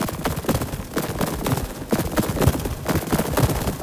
tx_perc_125_splayed.wav